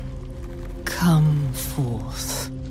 Download Come Forth Meme sound effect for free.